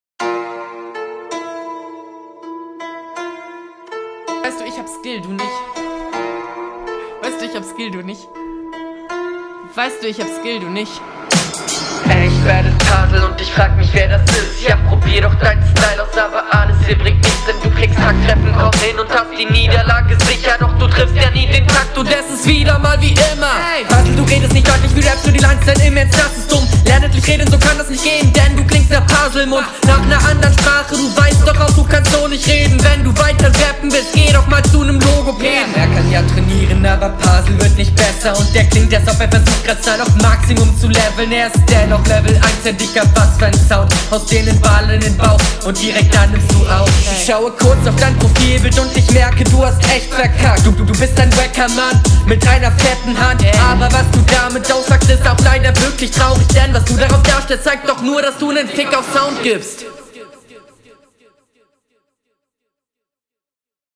Flow: Stimmeinsatz ist oke. Er ist manchmal etwas schwer verständlich, worunter die Delivery etwas leidet.